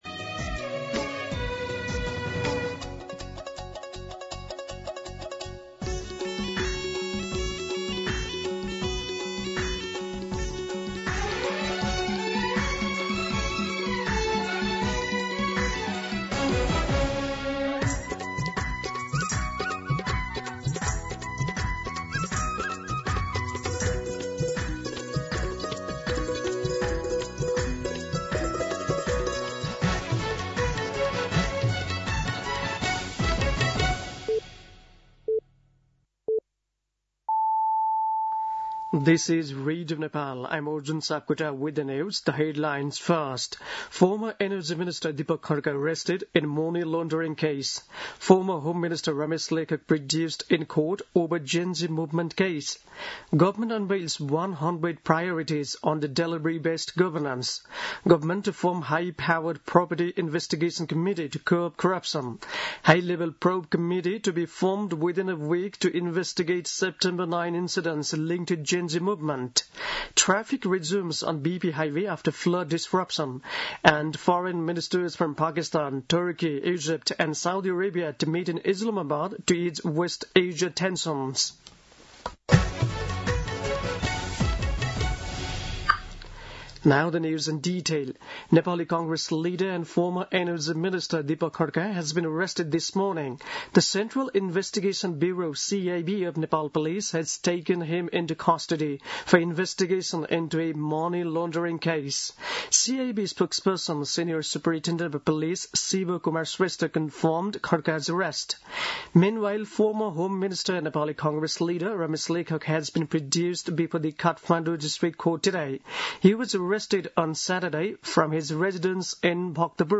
An online outlet of Nepal's national radio broadcaster
दिउँसो २ बजेको अङ्ग्रेजी समाचार : १५ चैत , २०८२